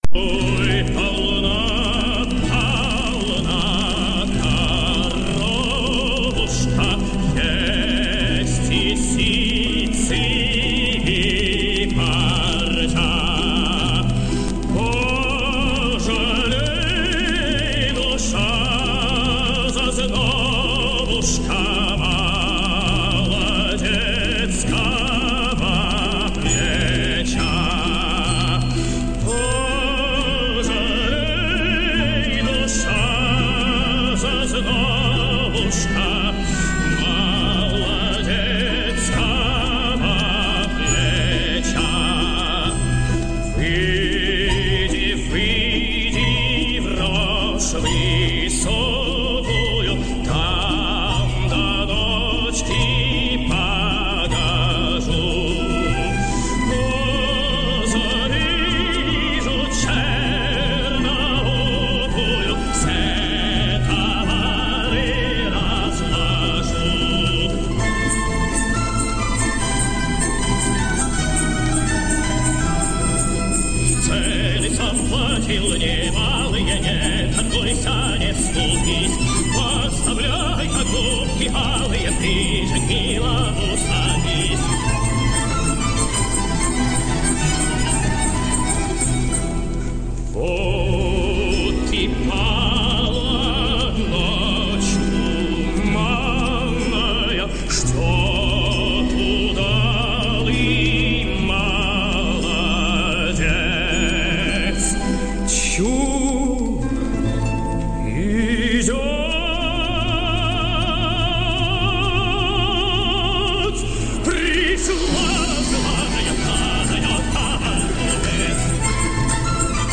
I was going more tword the old Russion folk song "The Korobeiniki", in which the tetris song came from.